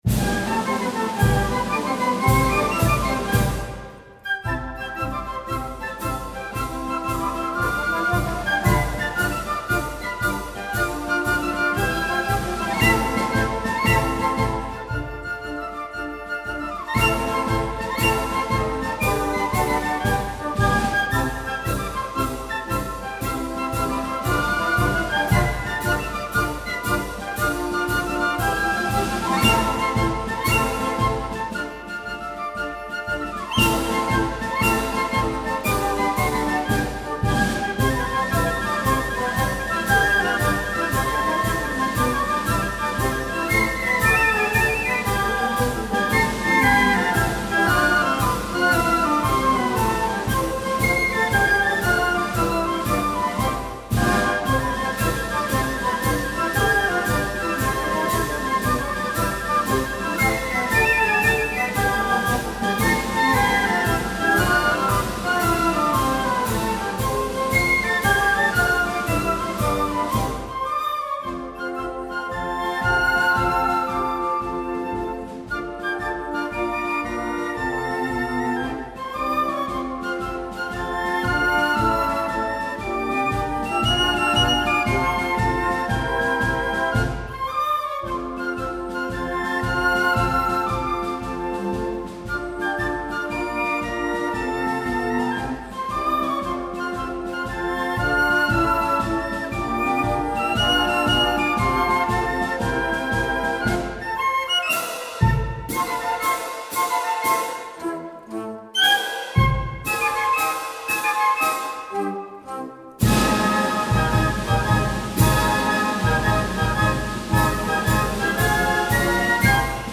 Flute bands are supposed to play marches - and why not?
MARCHES & MARCH COMPOSERS